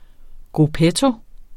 Udtale [ gʁuˈpεto ]